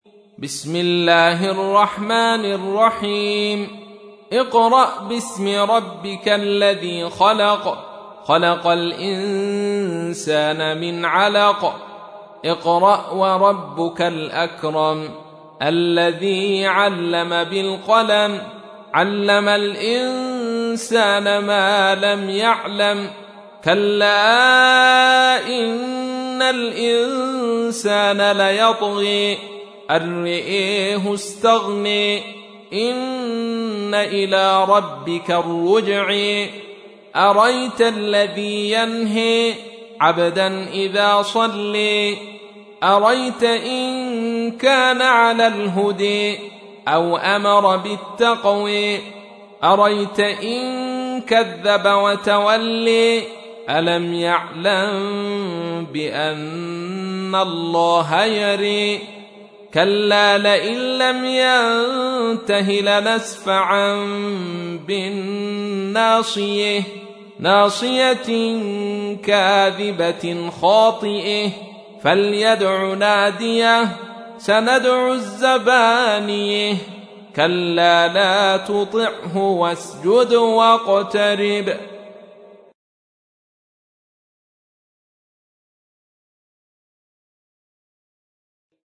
تحميل : 96. سورة العلق / القارئ عبد الرشيد صوفي / القرآن الكريم / موقع يا حسين